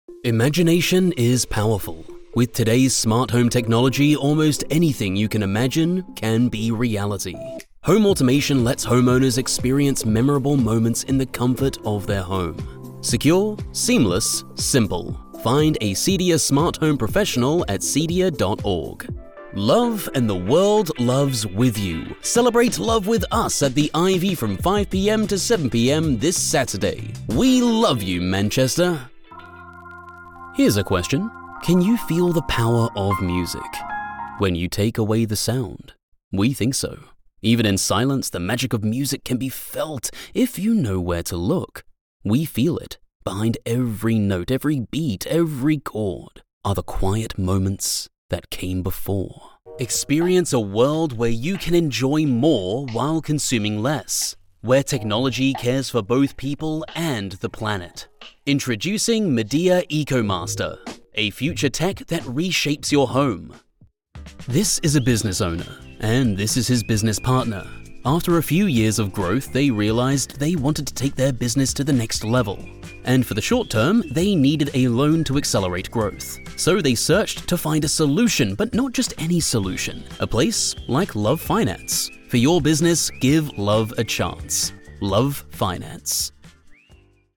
A confident baritone–low tenor range, with natural authority and a smooth, controlled warmth that reads immediately professional.
Clean and polished, with a subtle velvet grit. Effortless and unforced.
Television Spots
British Warm Personable